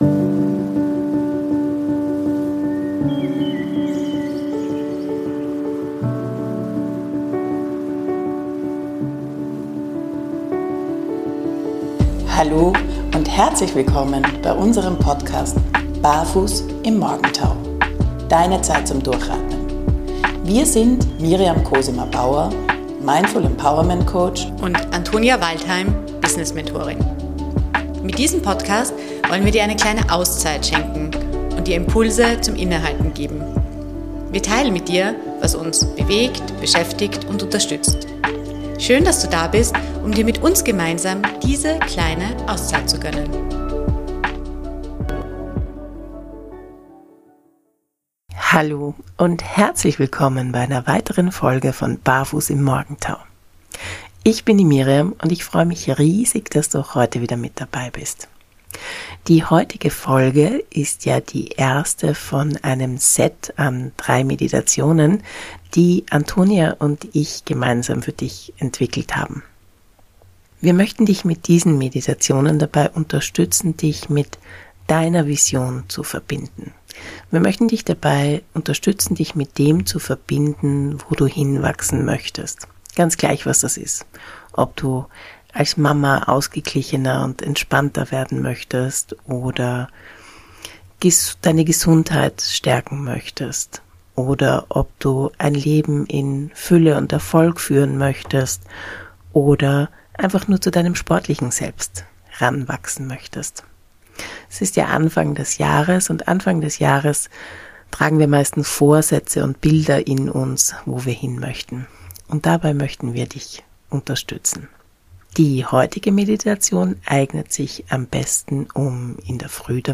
Beschreibung vor 1 Jahr Heute haben wir etwas ganz Besonderes für dich: Die erste Meditation einer dreiteiligen Serie, die dich dabei unterstützen sollen, dich mit deiner inneren Version zu verbinden und sie Wirklichkeit werden zu lassen.